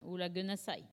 Collectif-Patois (atlas linguistique n°52)
Catégorie Locution